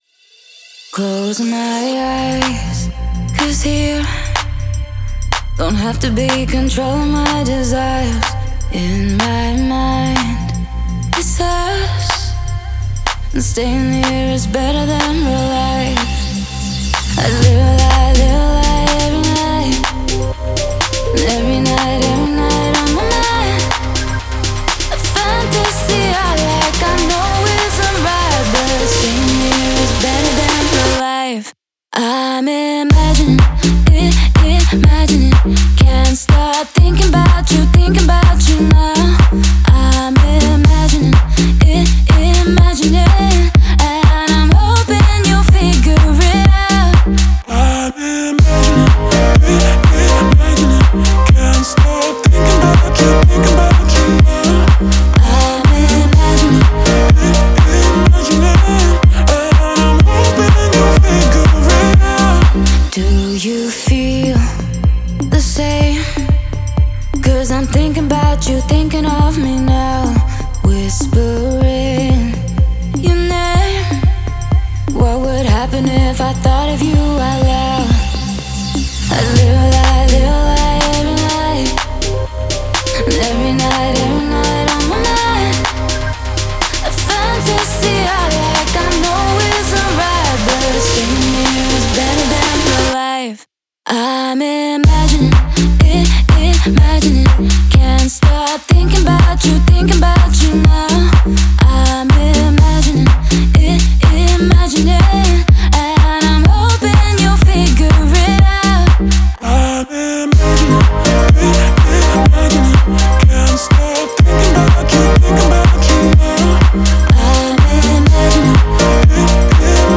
энергичная электронная поп-композиция